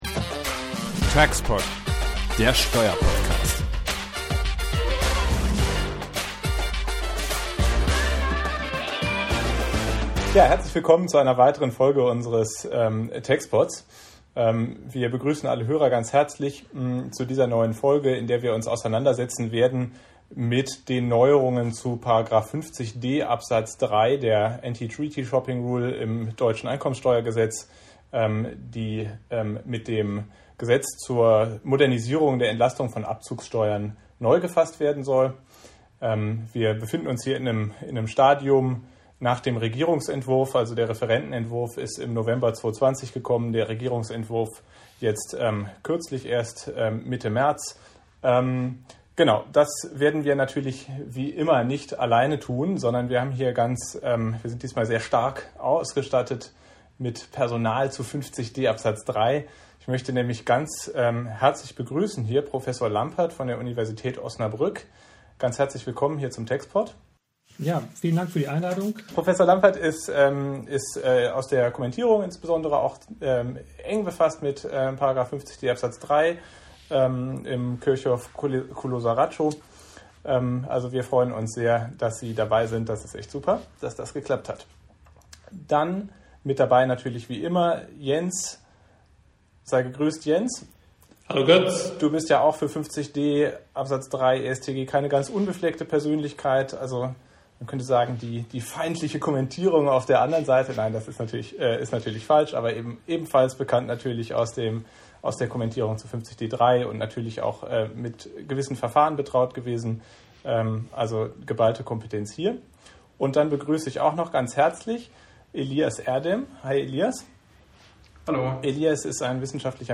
Eine rege Diskussion zum Entwurf des § 50d Abs. 3 EStG im Gesetz zur Modernisierung der Entlastung von Abzugsteuern und der Bescheinigung der Kapitalertragsteuer.